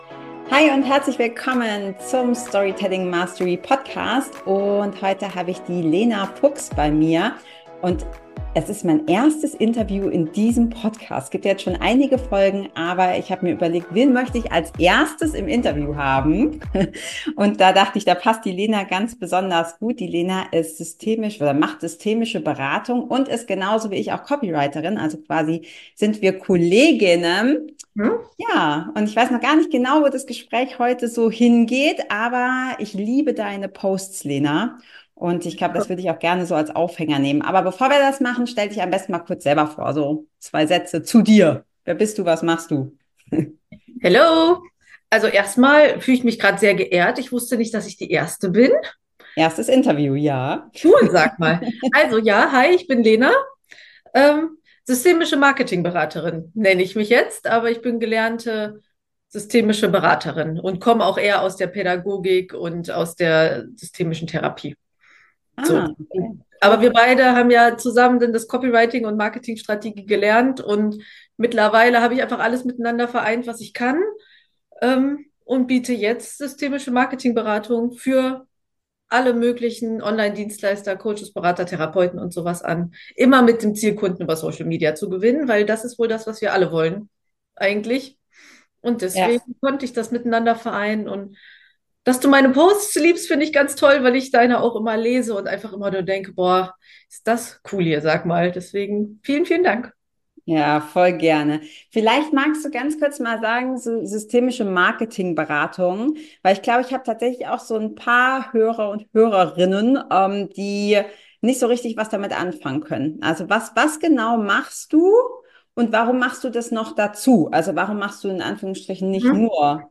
#12 Wie du Kunden über Social Media gewinnst (Interview